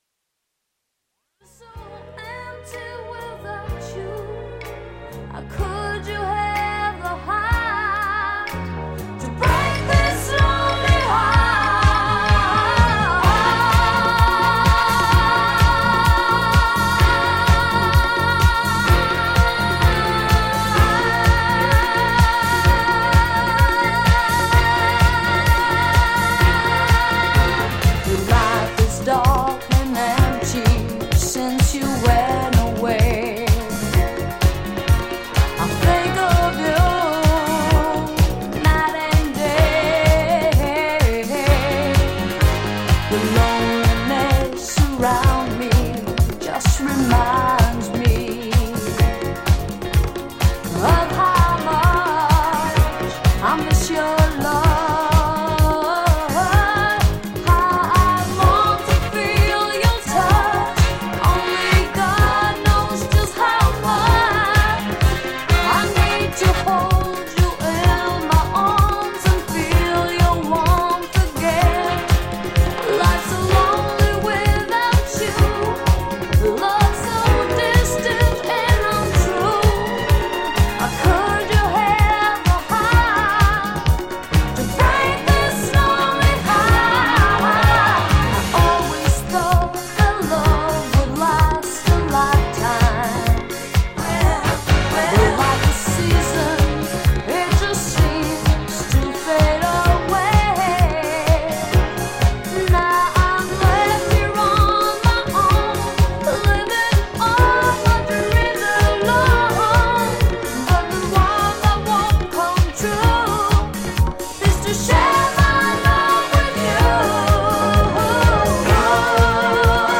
ジャンル(スタイル) DISCO / SOUL /FUNK